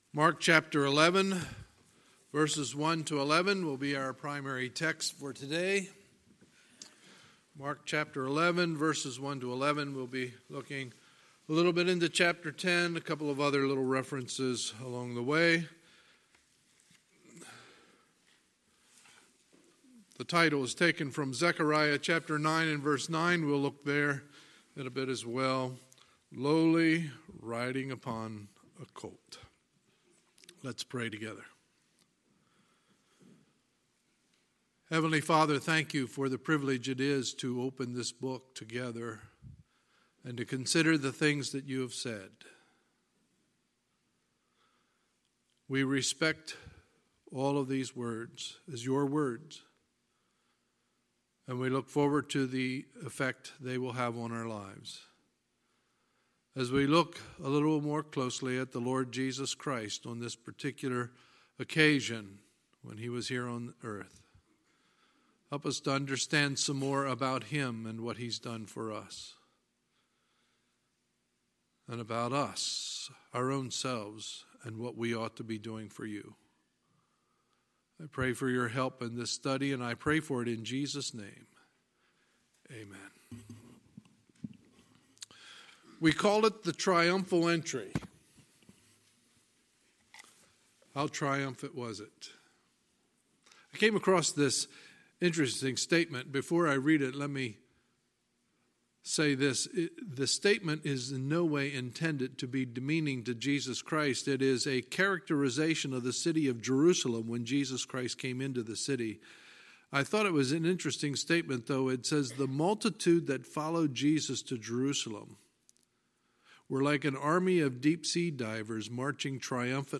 Sunday, April 14, 2019 – Sunday Morning Service